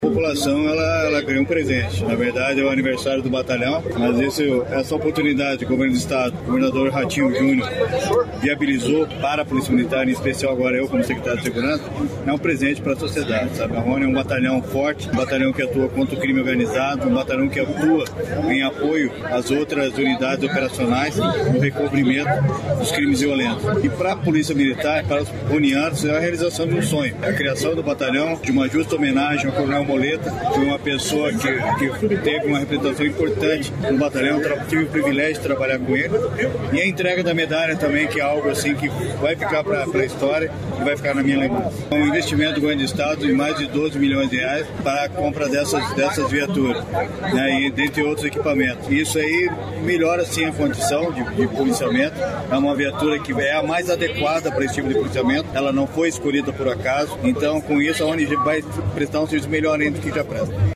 Sonora do secretário da Segurança Pública, Hudson Teixeira, sobre o aniversário da BPRone e entrega de 38 novas viaturas para reforço do policiamento no Estado